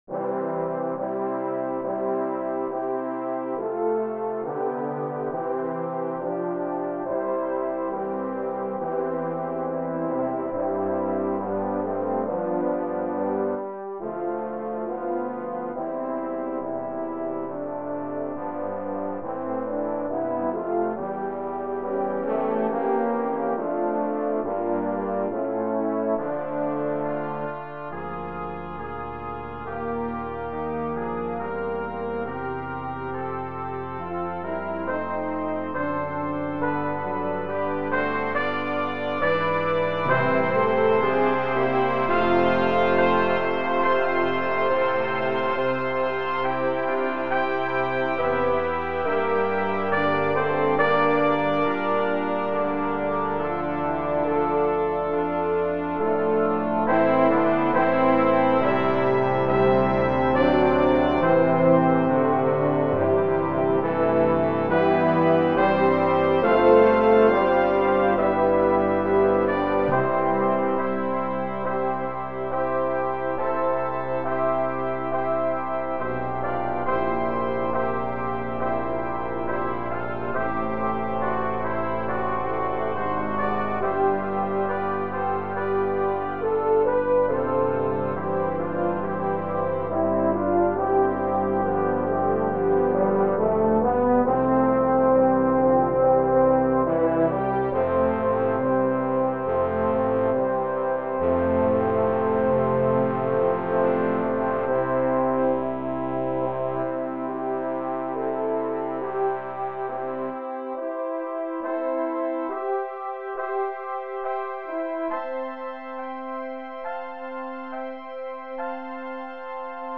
Genre Klassik